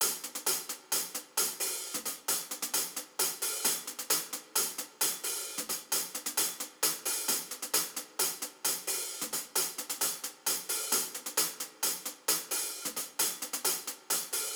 Berries Hi Hat Loop.wav